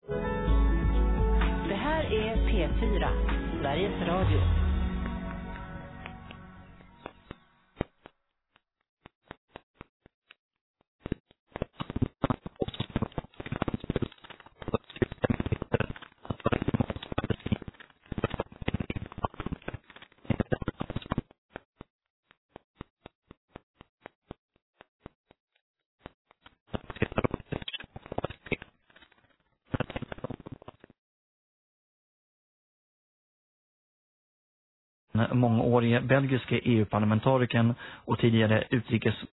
But when the Live Feed started, there was a crackling noise.
Live Feed problem.mp3 (96 KB)